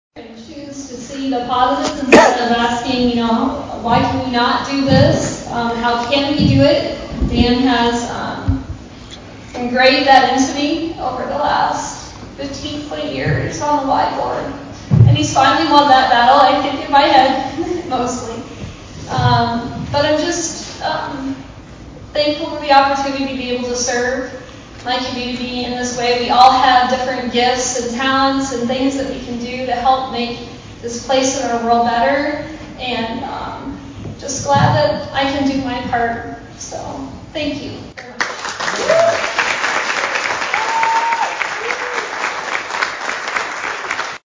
(Atlantic) – Each year, the Atlantic Area Chamber of Commerce honors an outstanding community leader with its highest accolade, the Distinguished Service Award, presented at the Annual Awards Banquet on Friday evening.
The Annual Atlantic Chamber of Commerce Awards Banquet was held at The Venue in downtown Atlantic.